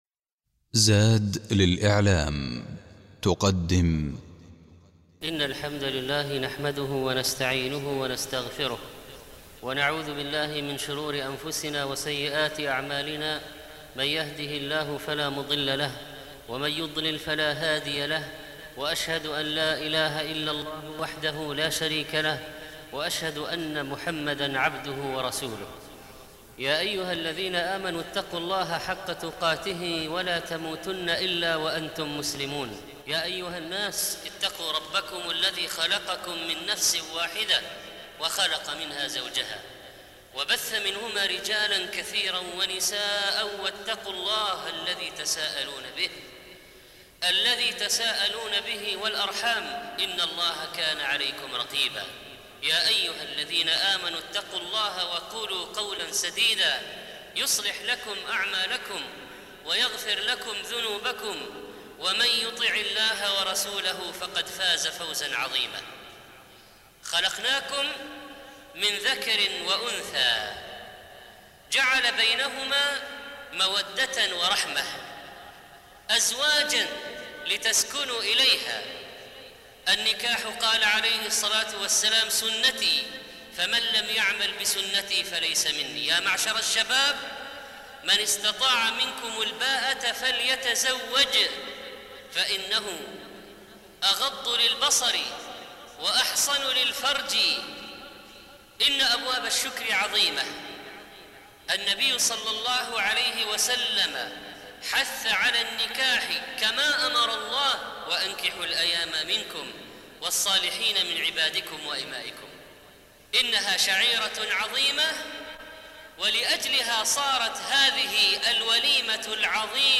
الخطبة الأولى مشروعية الوليمة وإجابة الدعوة إليها